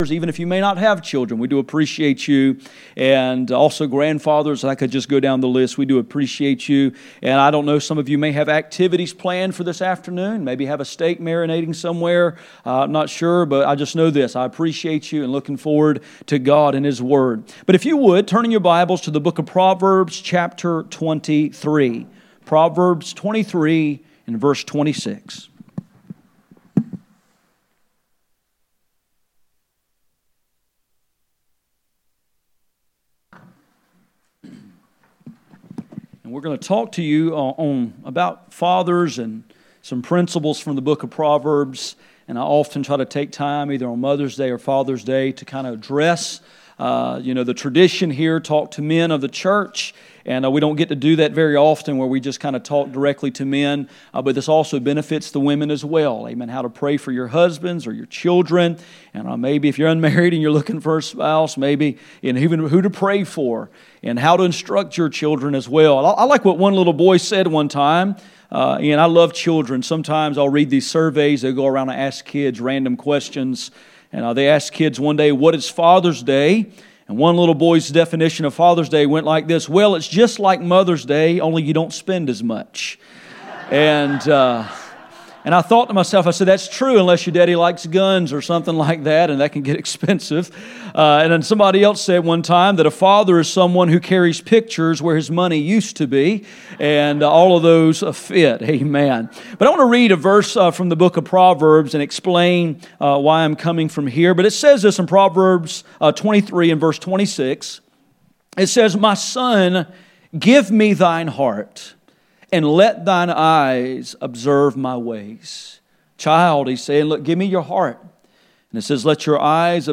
None Service Type: Sunday Morning %todo_render% « The baptism of the Holy Ghost